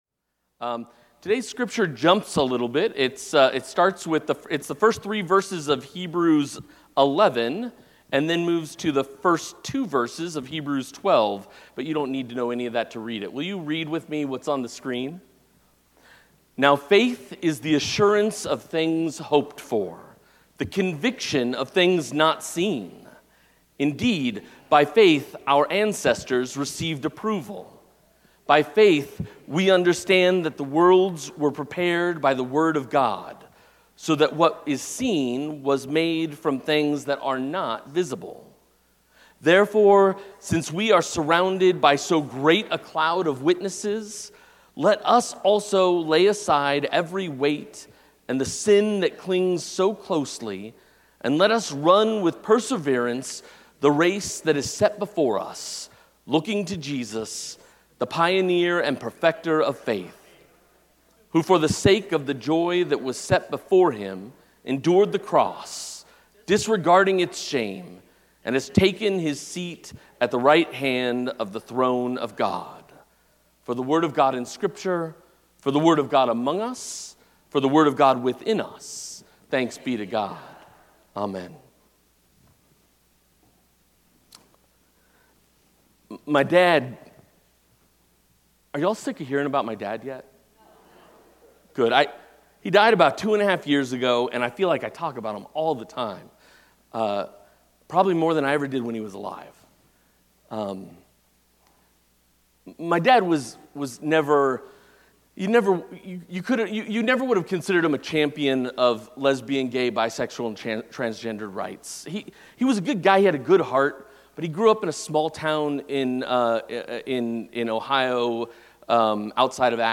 Sermons | Broadway United Methodist Church